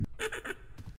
GiggleLaugh.mp3